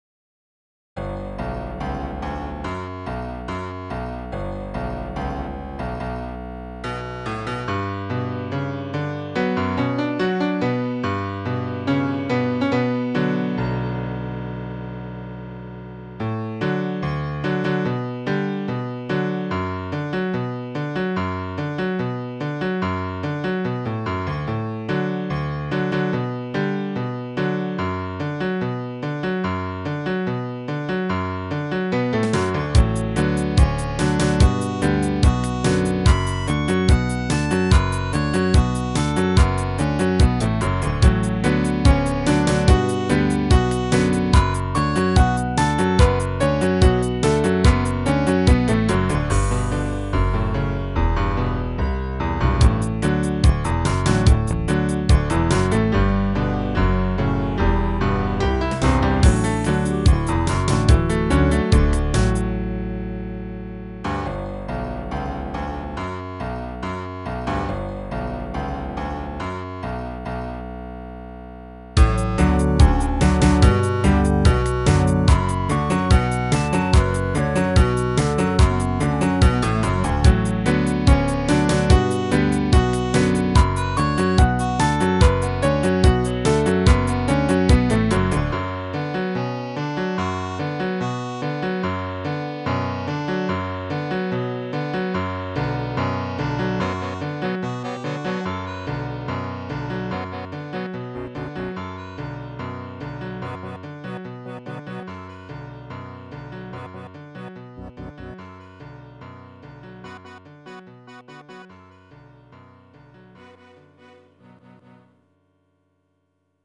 Reminds me of old-time cowboy music, but a bit more melancholy.